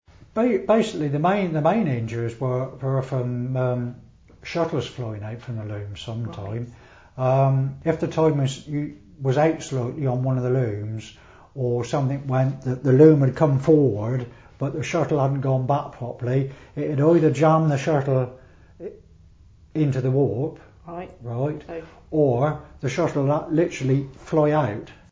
Communication in the weaving sheds was through lip reading and gesture, you might have to shout or making a calling noise to get someone's attention - ear plugs were not provided.
demonstrates how weavers would get each other's attention over the noise of the looms